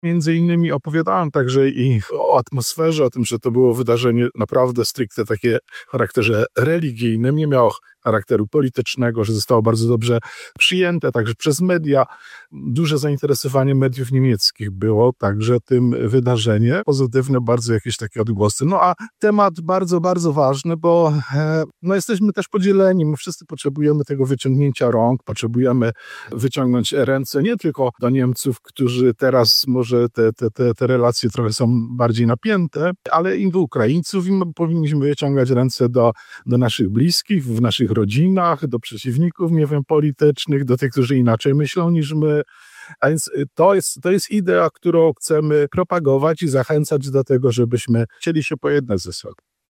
03_Abp-Kupny-po-audiencji.mp3